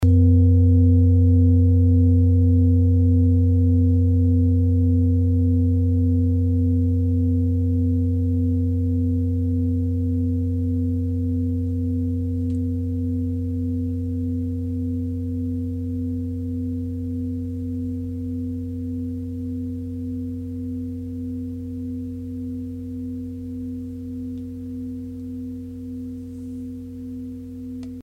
Klangschale Nepal Nr.42
(Ermittelt mit dem Filzklöppel oder Gummikernschlegel)
Wenn man die Frequenz des Mittleren Sonnentags 24mal oktaviert, hört man sie bei 194,18 Hz.
Auf unseren Tonleiter entspricht er etwa dem "G".
klangschale-nepal-42.mp3